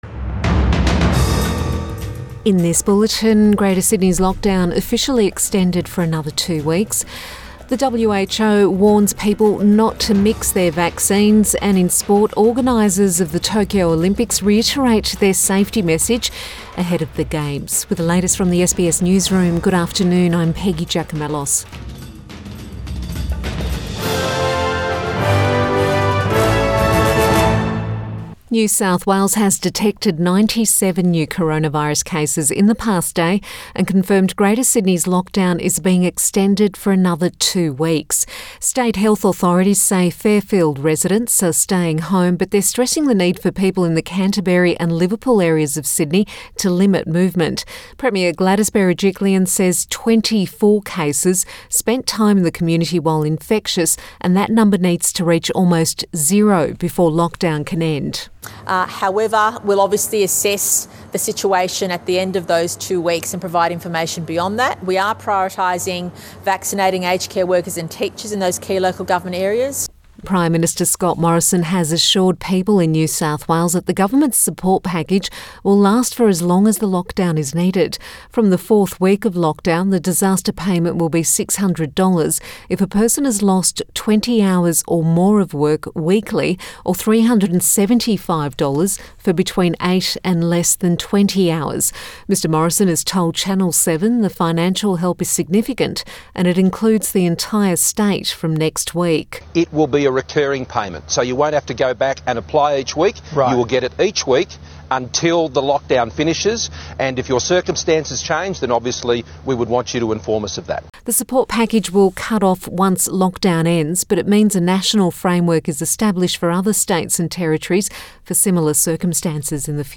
Midday bulletin 14 July 2021